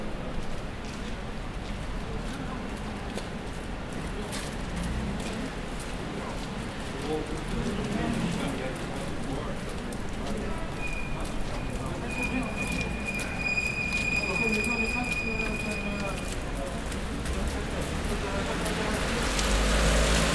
海外の音２ フランス（一部イギリス）
雨上がり街３
AmbRueGaiteApresLaPluie3.mp3